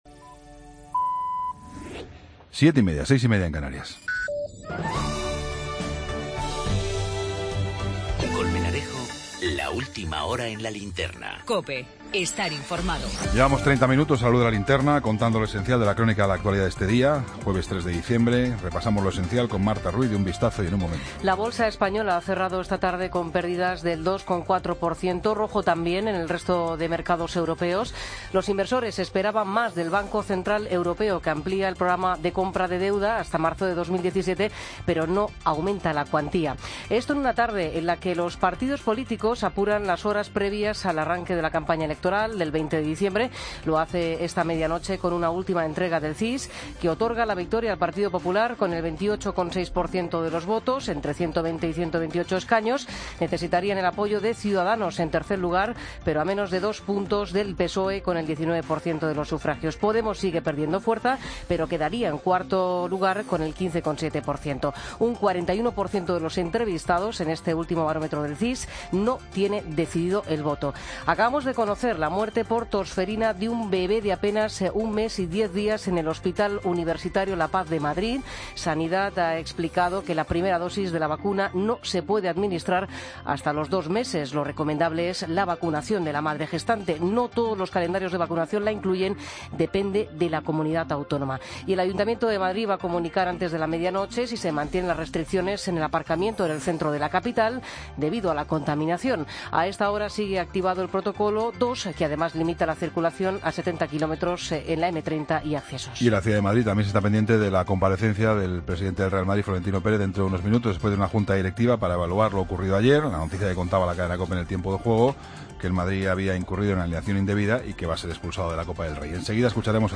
AUDIO: Toda la información con Juan Pablo Colmenarejo. Ronda de corresponsales.